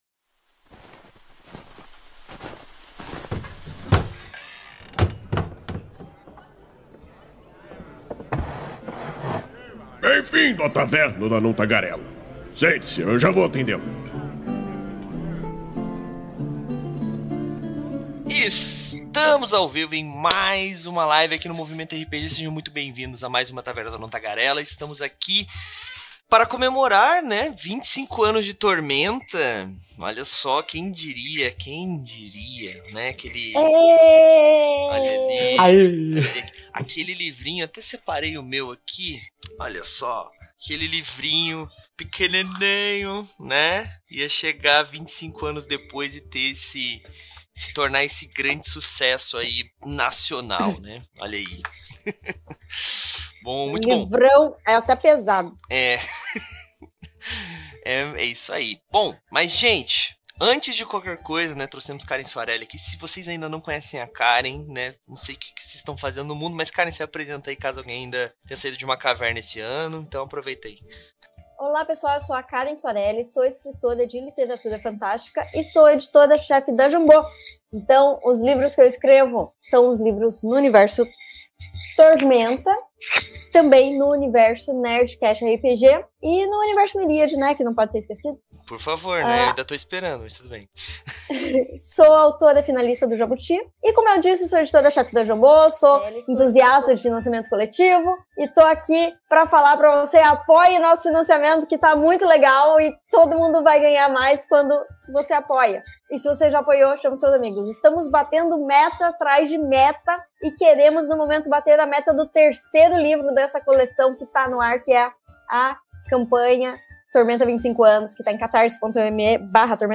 Venha saber o que esperar de novos conteúdos no aniversário de 25 anos do maior RPG do Brasil. A Taverna do Anão Tagarela é uma iniciativa do site Movimento RPG, que vai ao ar ao vivo na Twitch toda a segunda-feira e posteriormente é convertida em Podcast.